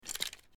Звук берут в руки оружие со стола